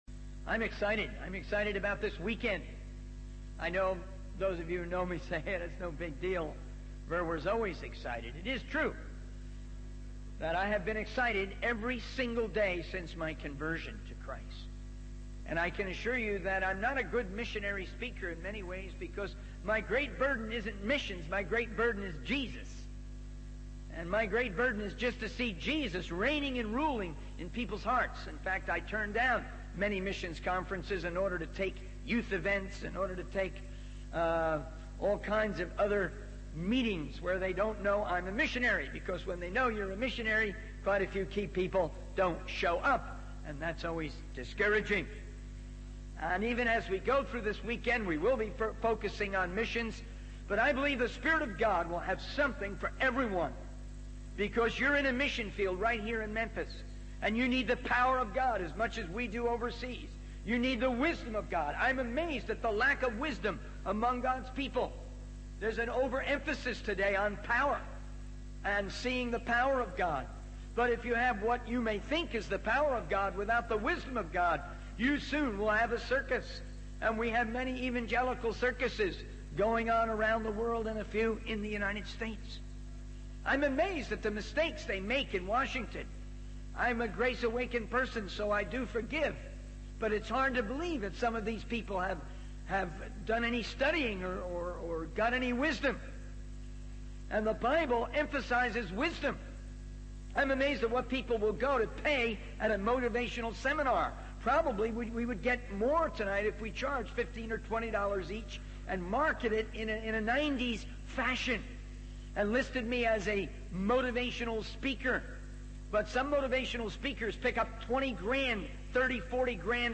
In this sermon, the speaker shares a vision for missionary sending based on the word of God.